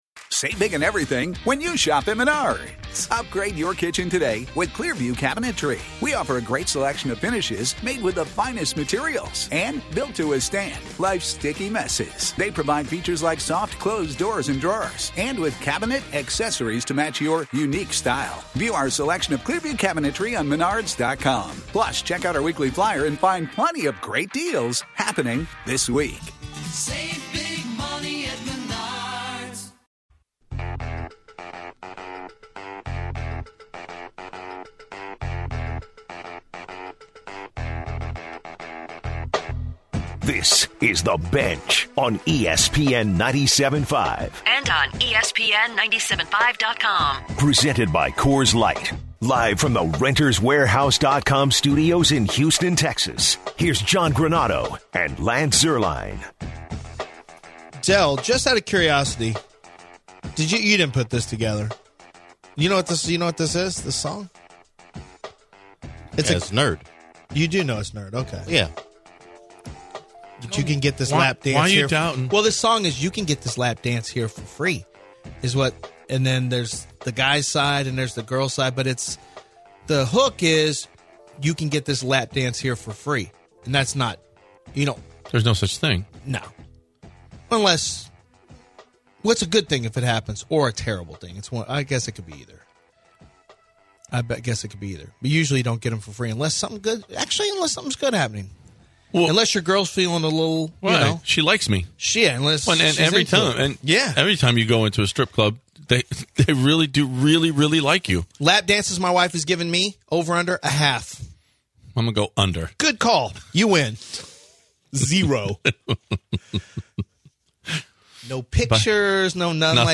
He compares James Harden to the Great Michael Jordan they also talk about how there are peanut butter and jelly sandwiches in every locker room. Hall of fame Wide Reciever Tim Brown joins the program as well.